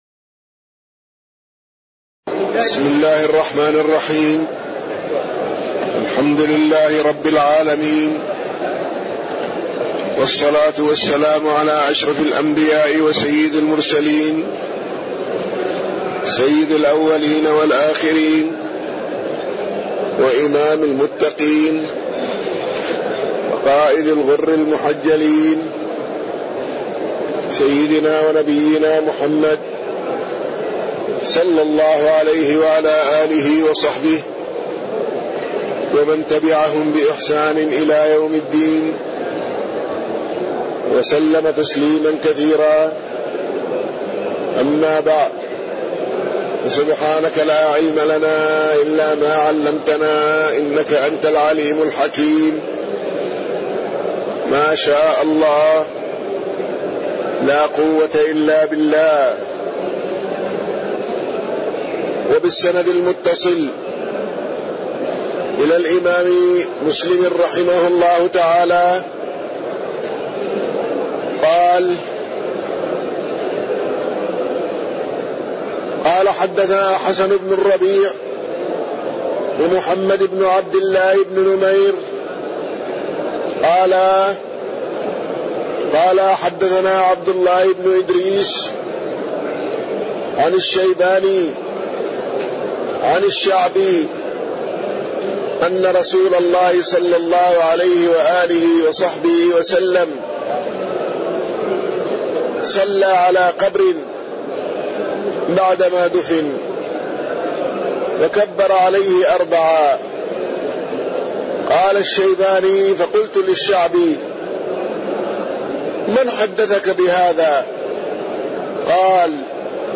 تاريخ النشر ٢ ربيع الثاني ١٤٣٢ هـ المكان: المسجد النبوي الشيخ